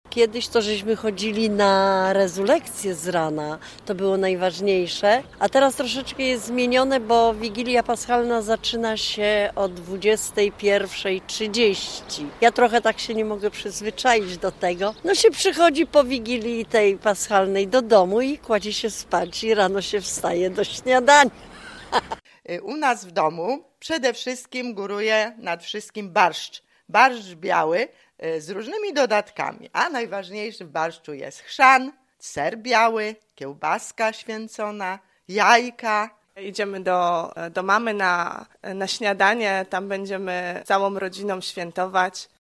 O Wielkanocny poranek i związany z nim tradycje zapytaliśmy mieszkańców Gorzowa